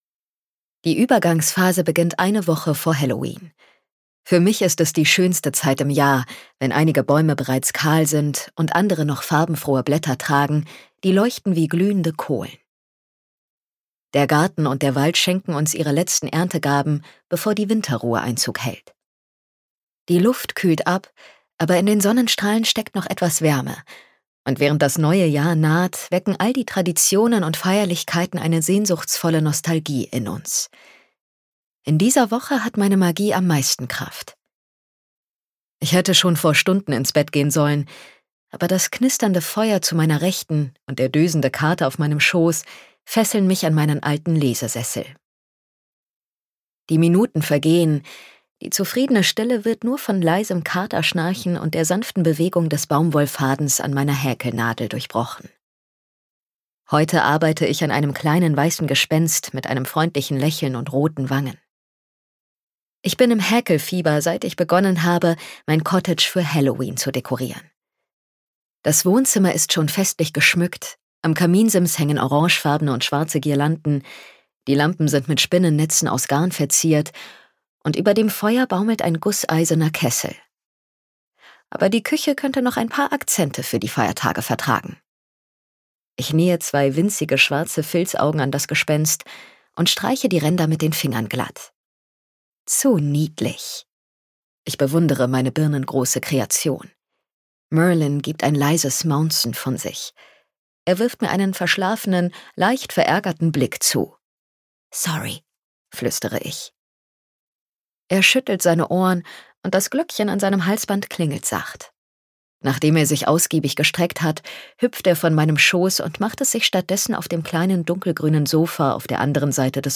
Gekürzt Autorisierte, d.h. von Autor:innen und / oder Verlagen freigegebene, bearbeitete Fassung.